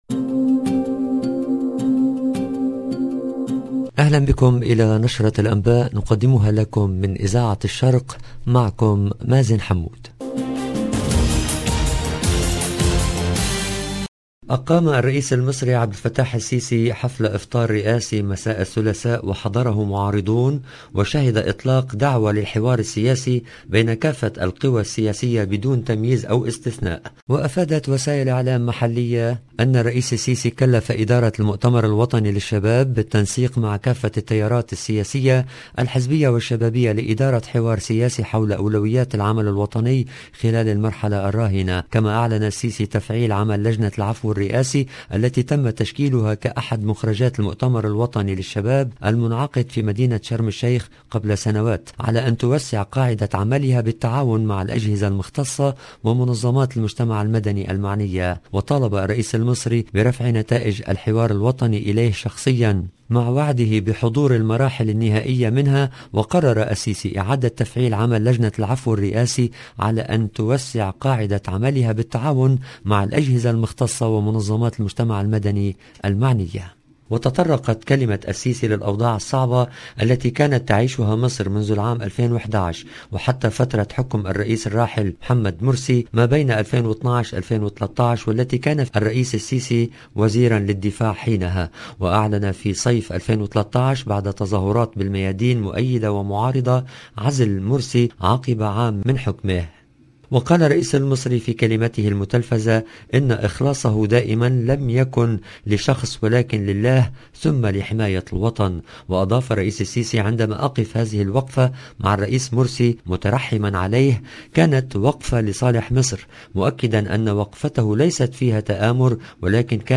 LE JOURNAL DU SOIR EN LANGUE ARABE DU 27/04/22